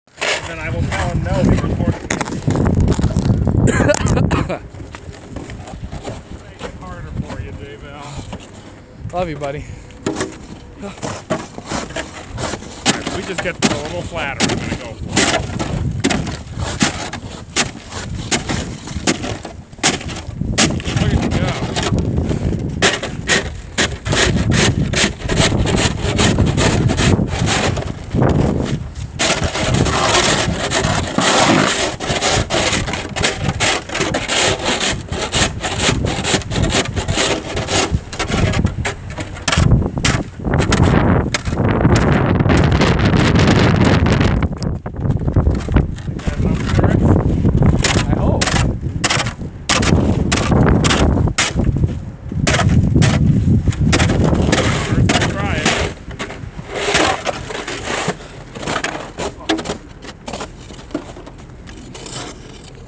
Field Recording – Breaking Snow and Ice
strong wind , Talking
snow-Field.wav